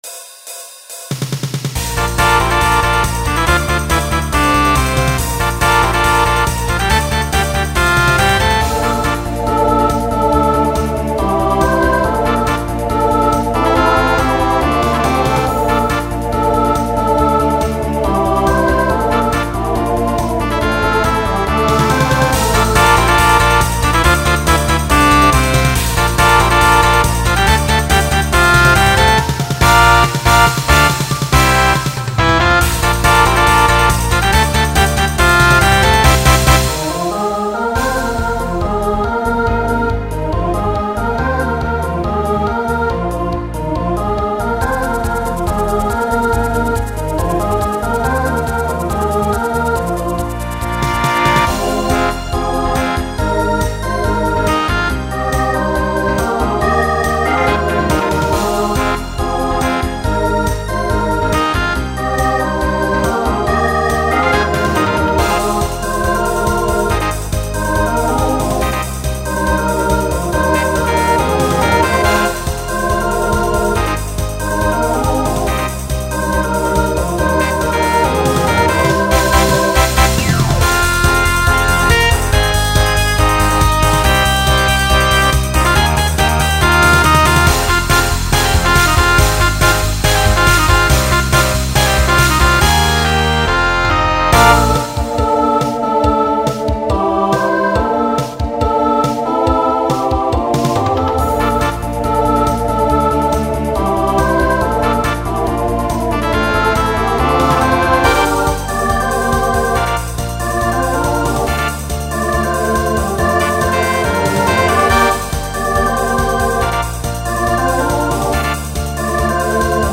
Genre Pop/Dance Instrumental combo
Voicing SAB